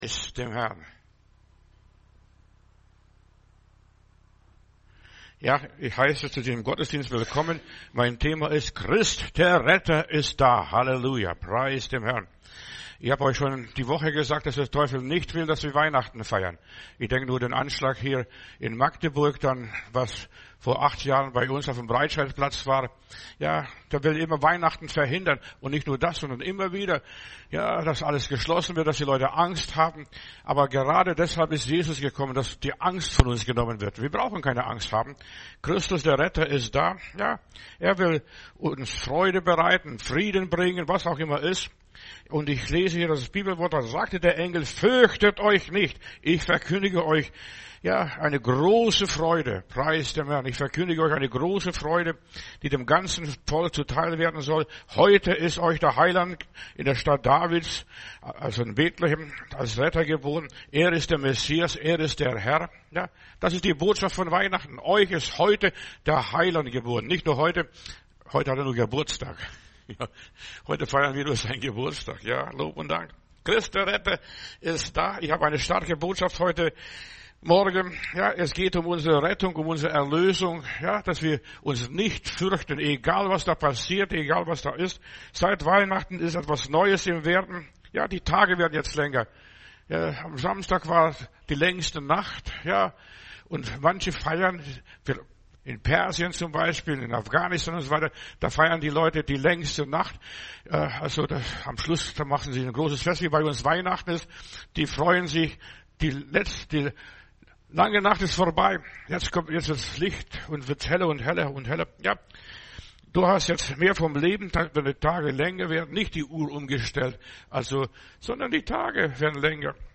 Predigt herunterladen: Audio 2024-12-25 Christ der Retter Video Christ der Retter